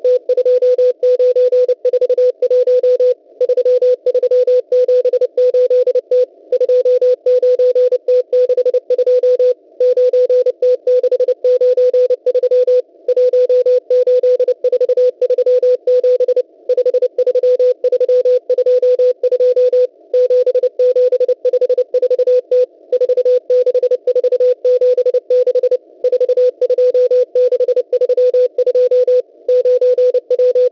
CW
8047 khz